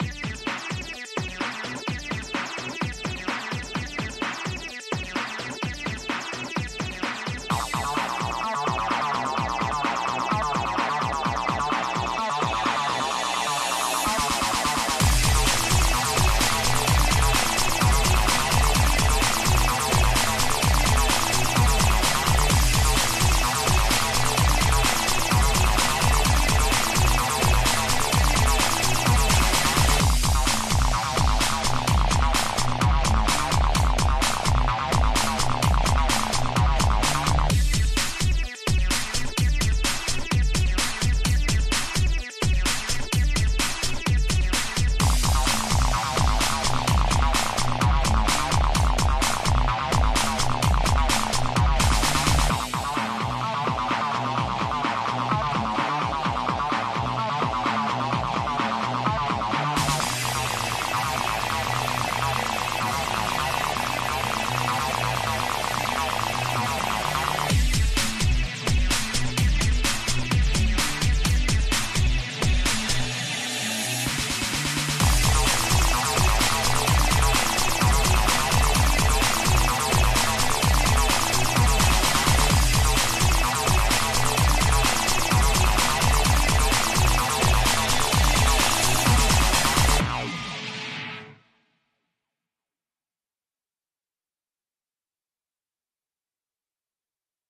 音楽は生き生きとしており、ビートは心地よく、人々の足元に響いている。